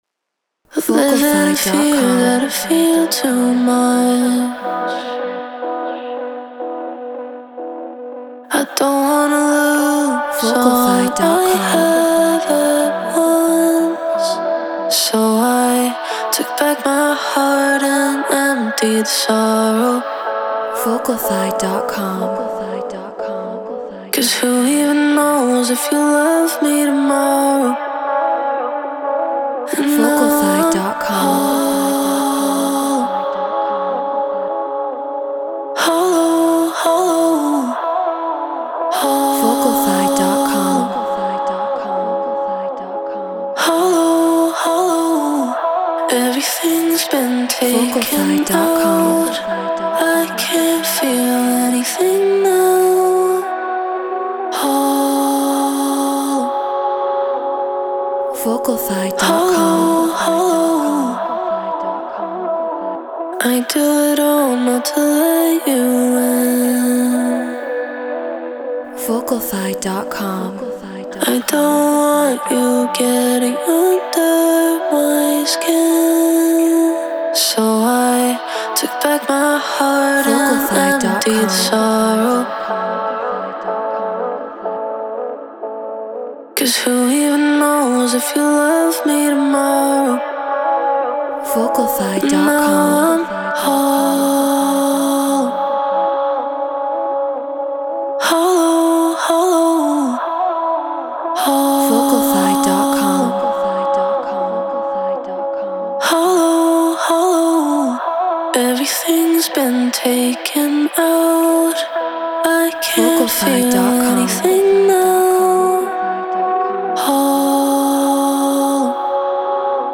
Deep House 123 BPM Bmin
Shure SM7B Apollo Solo Logic Pro Treated Room